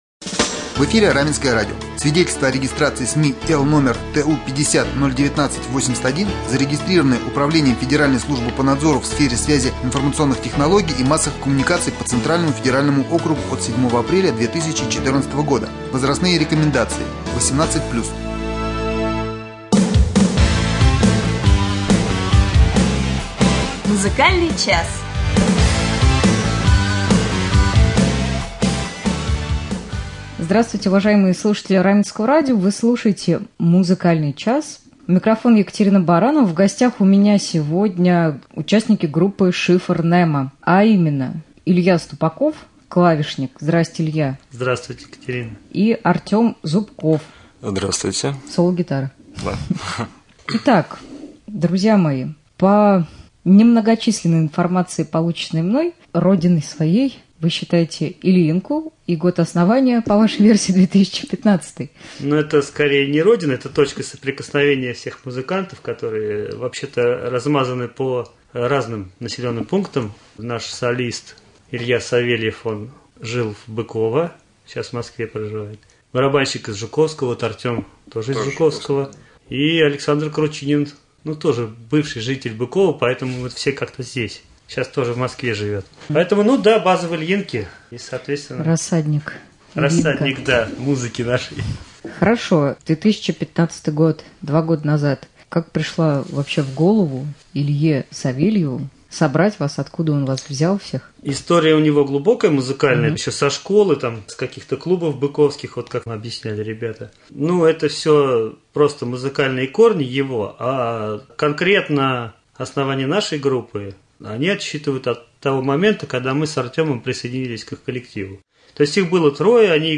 Гости студии — участники группы «Шифр Немо»